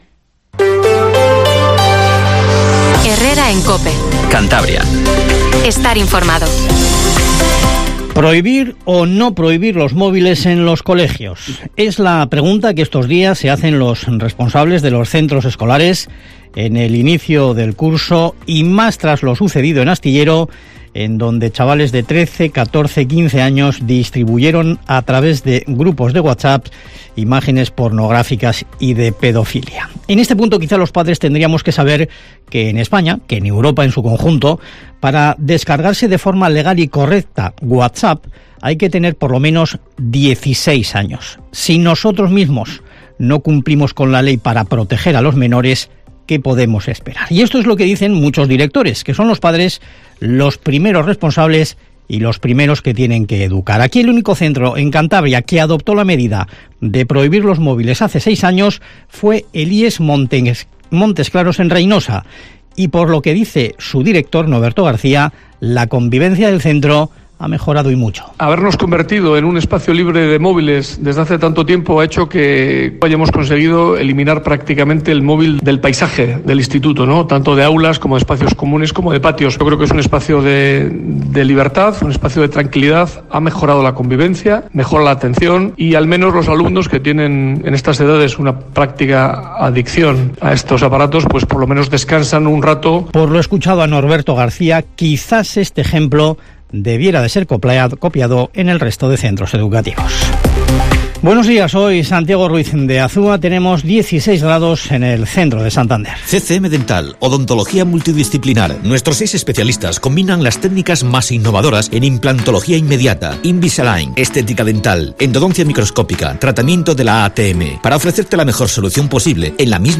Informativo HERRERA en COPE CANTABRIA 08:24